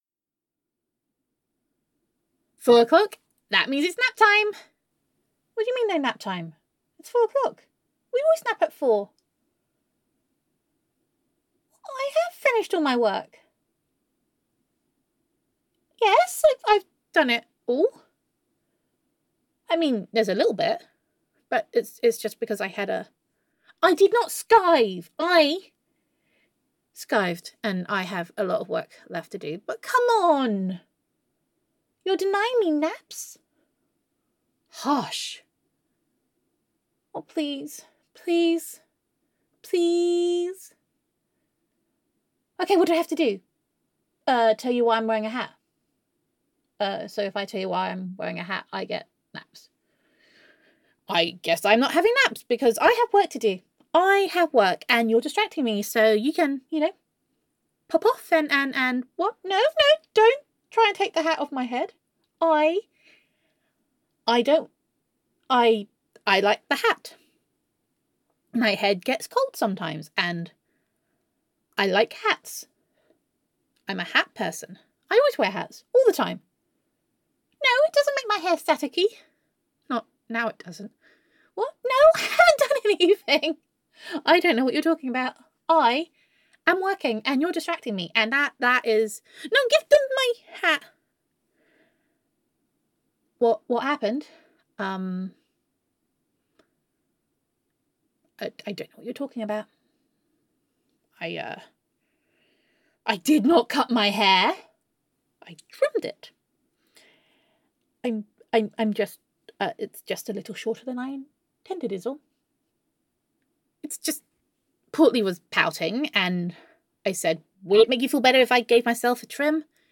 [F4A] Day Five - Keep It Under Your Hat [Girlfriend Roleplay][Self Quarantine][Domestic Bliss][Gender Neutral][Self-Quarantine With Honey]